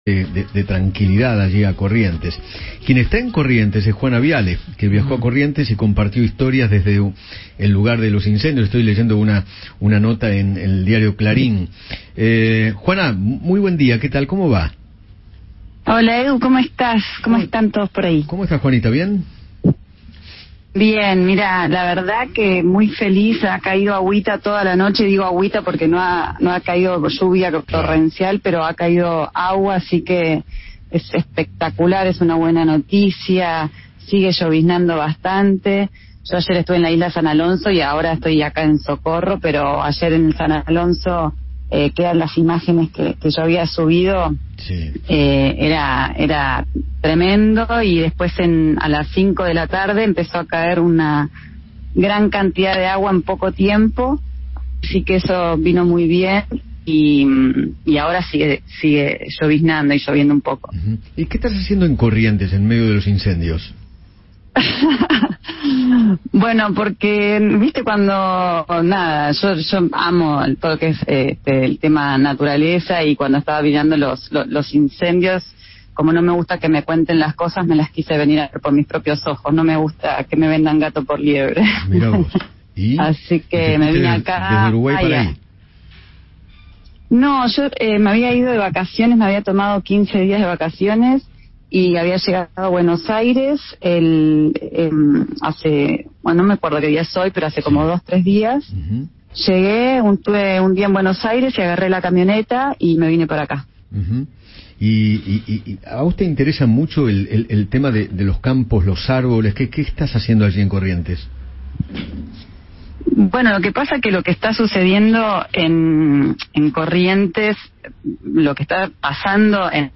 Juana Viale, conductora de televisión, conversó con Eduardo Feinmann sobre su viaje a Corrientes en medio de los incendios y relató cómo se encuentra la provincia, tras algunas lluvias.